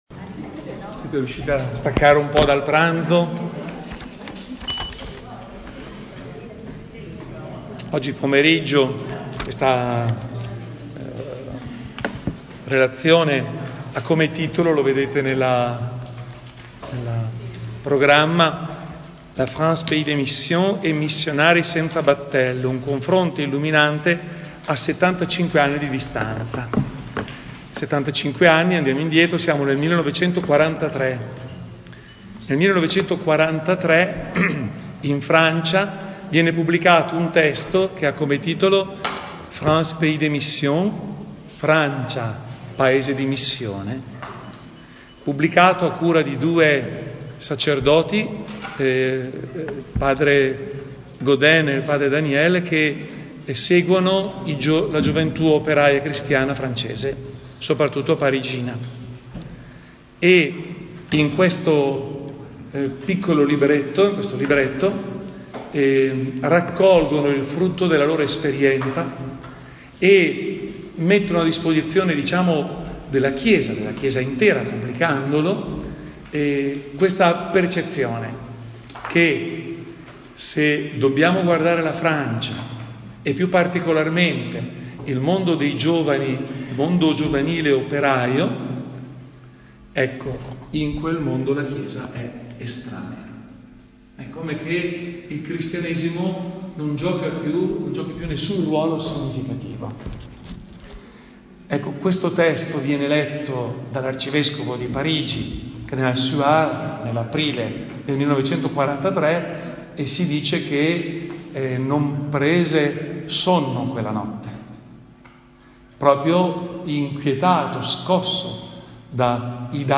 Interventi del Convegno